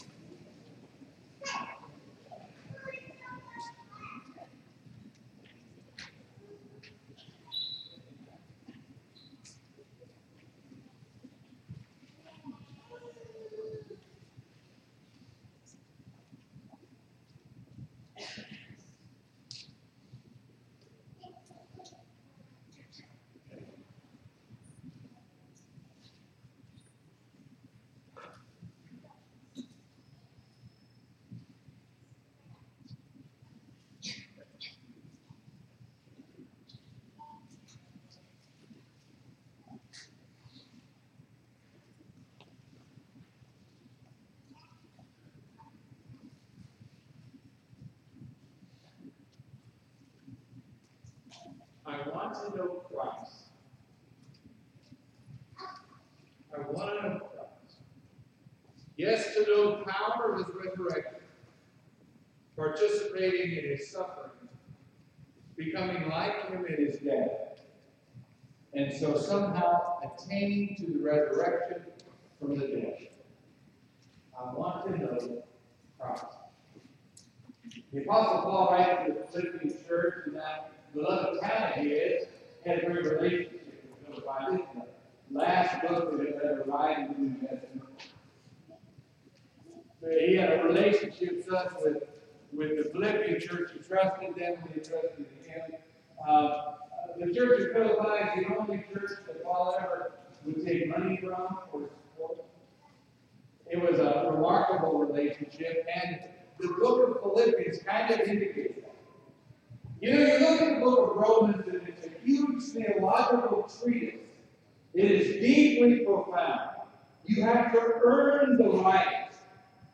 Sermon:
sermon-philippians-3.mp3